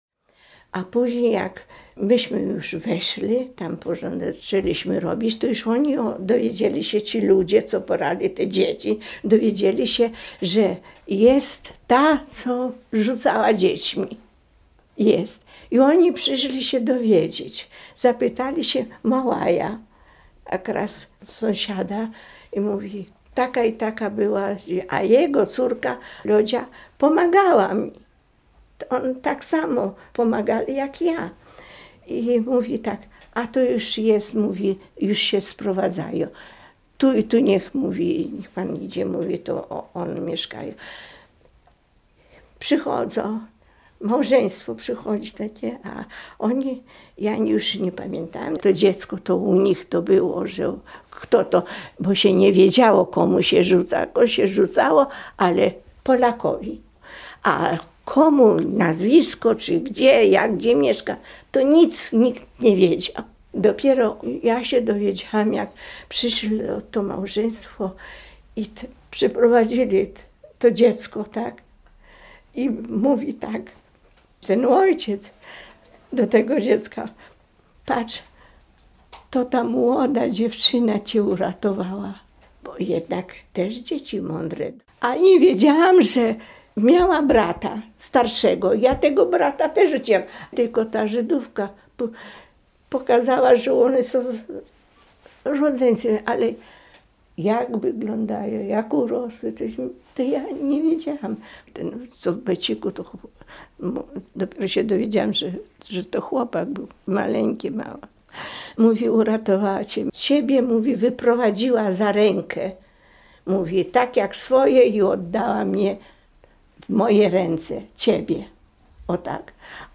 Relacja mówiona zarejestrowana w ramach Programu Historia Mówiona realizowanego w Ośrodku